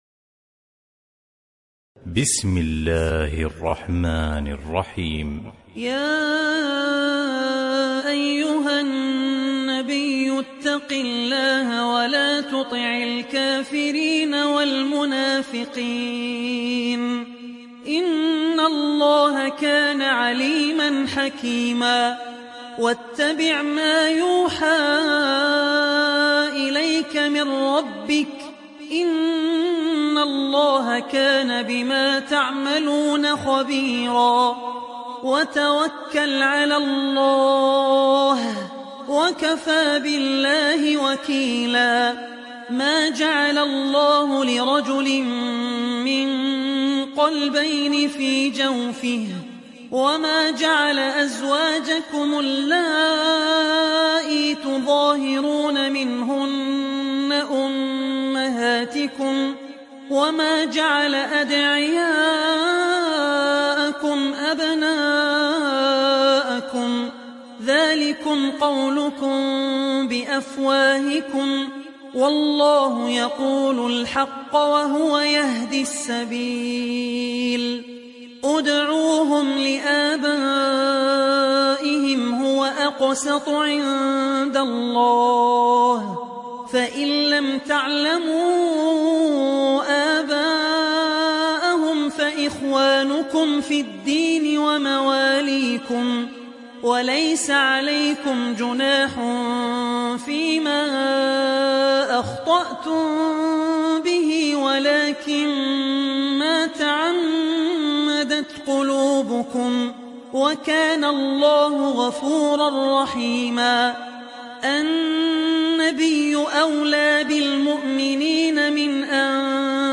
Surat Al Ahzab Download mp3 Abdul Rahman Al Ossi Riwayat Hafs dari Asim, Download Quran dan mendengarkan mp3 tautan langsung penuh